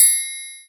090 - Triangle.wav